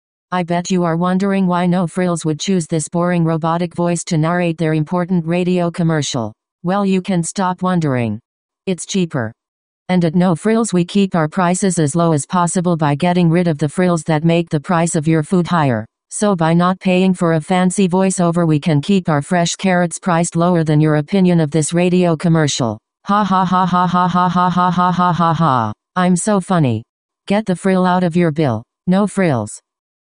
The cost of actors, studio time and post-production for radio ads ends up on your grocery bill, according to this No Frills’ campaign. Because the grocery retailer is all about saving customers money, it skipped most of those steps and used a cold robotic voice (think Siri’s less famous cousin) to narrate the scripts.